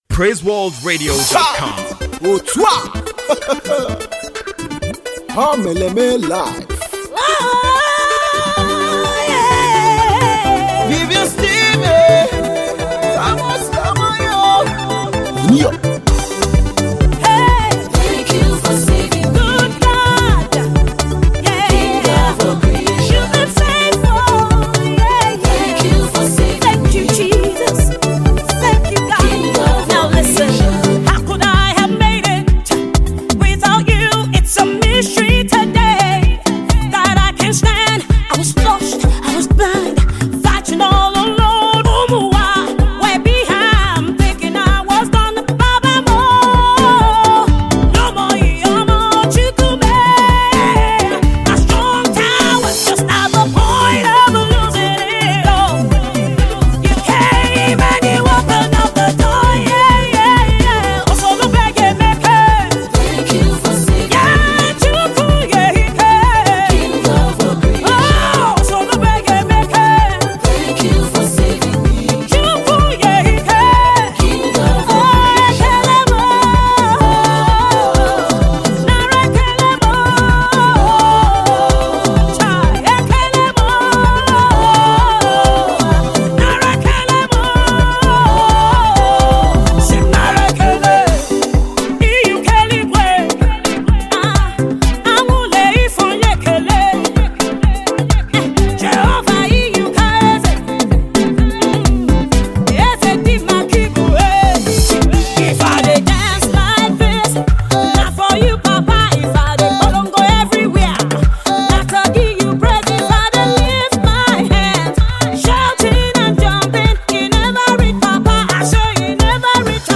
energetic praise track